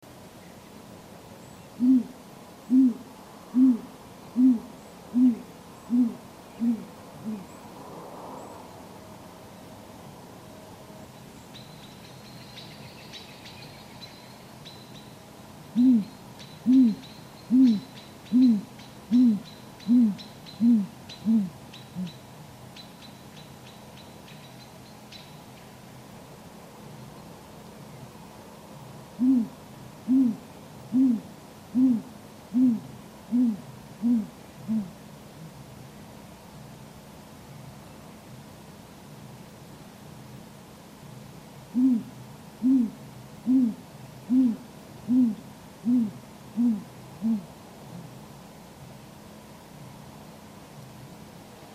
Great Grey Owl
Mnemonic ~ whoo-whoo-whoooo
great-grey-owl-song-c2a9xeno-canto.mp3